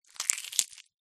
Звук разламываемого рака или краба (его руками разорвали пополам)